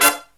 HIGH HIT15-R.wav